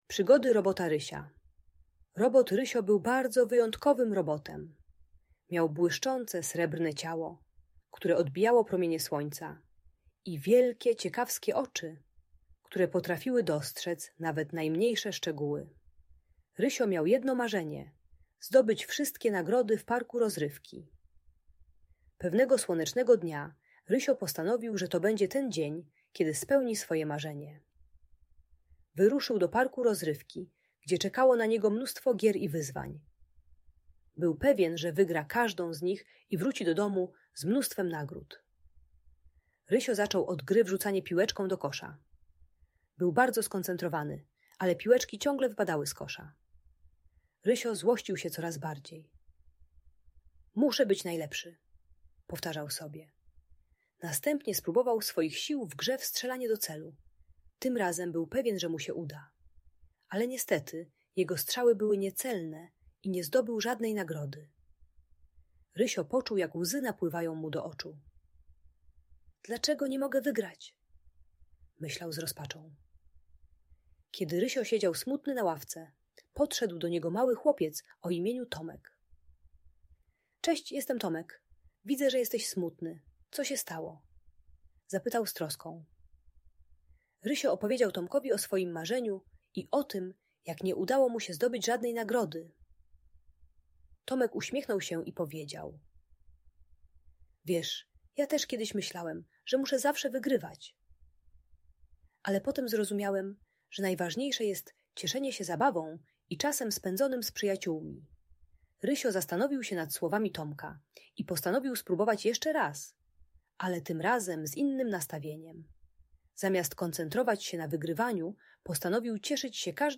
Ta audiobajka o radzeniu sobie z przegraną uczy, że nie trzeba zawsze wygrywać, by być szczęśliwym. Pomaga dziecku zrozumieć wartość zabawy i przyjaźni ponad rywalizację.